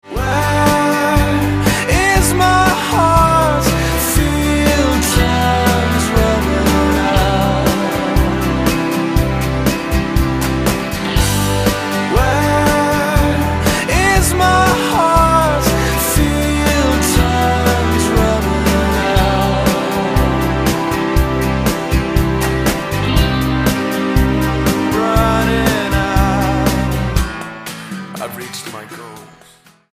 STYLE: Rock
bass
keyboard
vocals